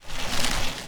bikemove.ogg